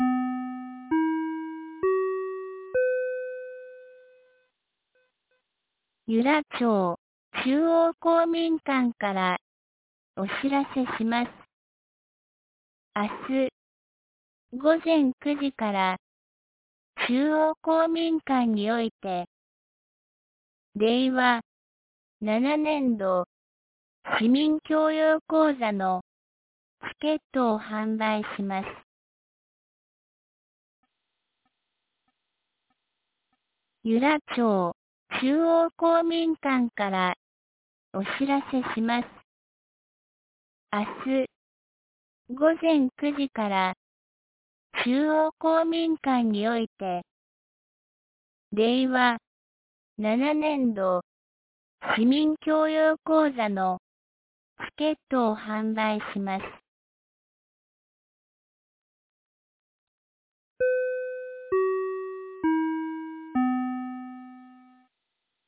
2025年05月21日 07時51分に、由良町から全地区へ放送がありました。